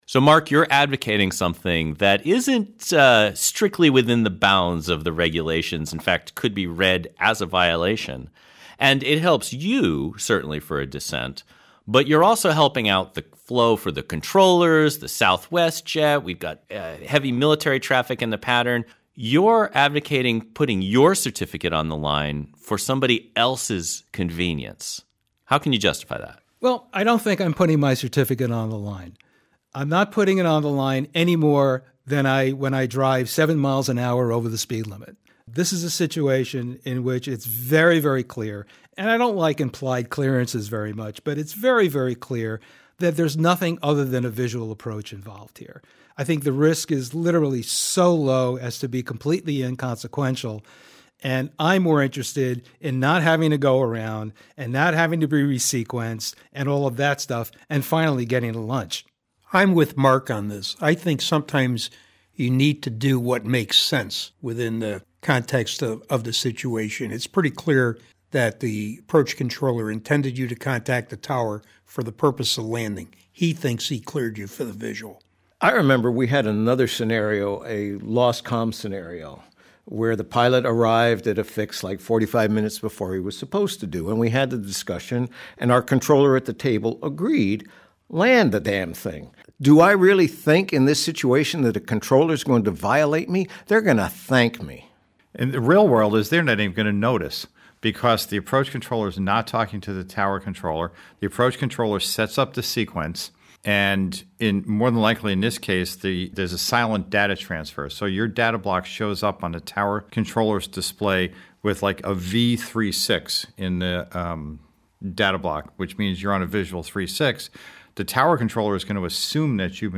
Not_cleared_for_the_visual_roundtable.mp3